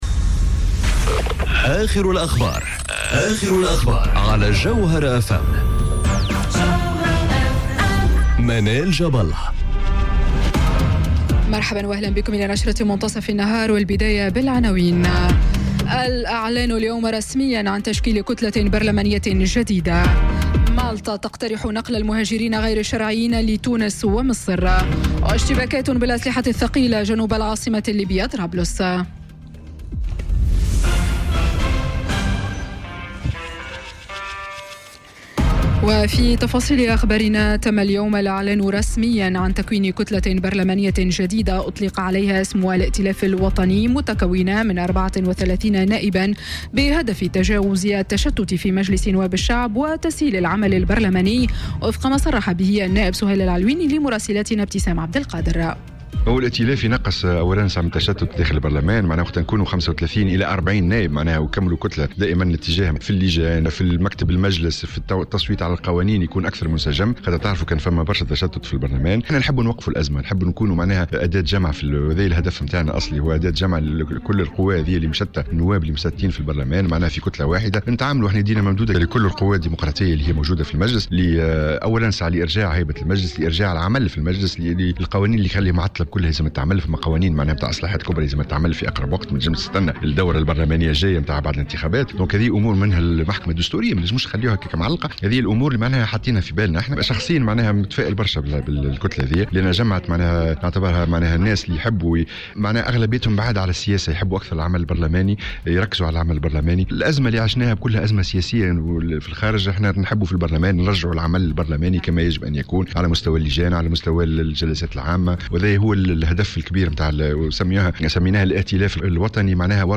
نشرة أخبار منتصف النهار ليوم الإثنين 27 أوت 2018